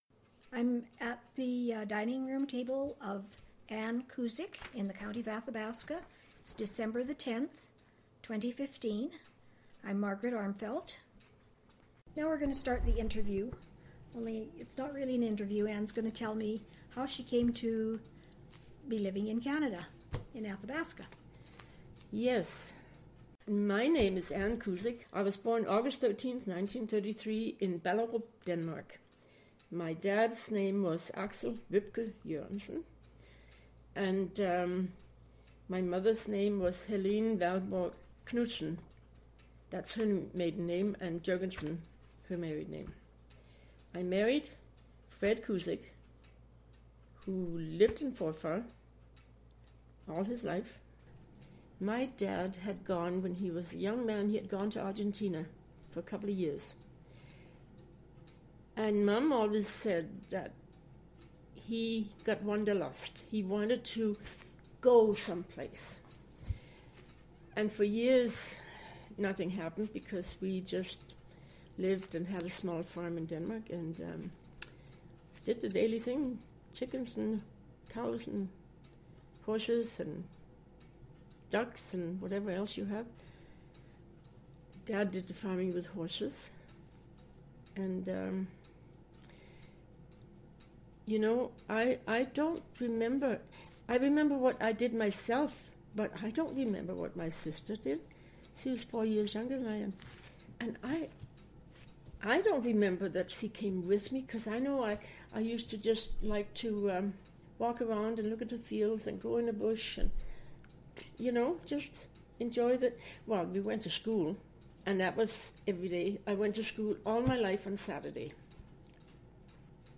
Audio interview and transcript of audio interview,